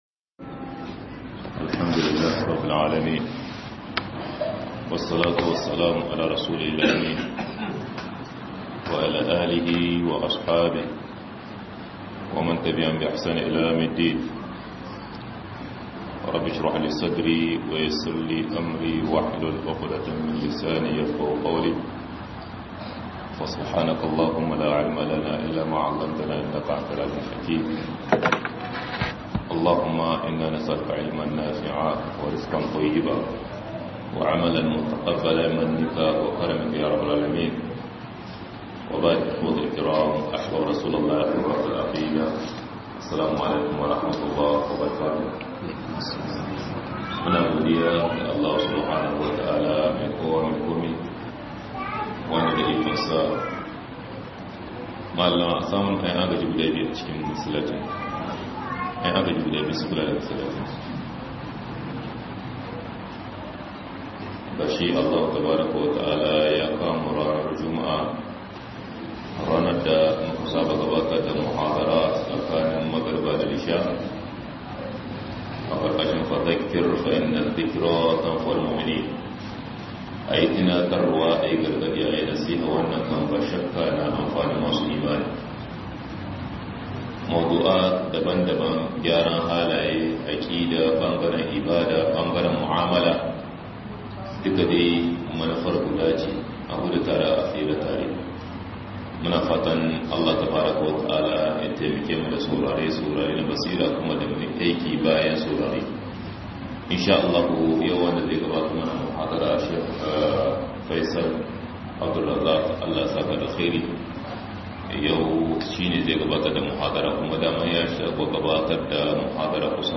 HALIN DA MUKE CIKI A YANZU 2023-09-15_18'52'04' - MUHADARA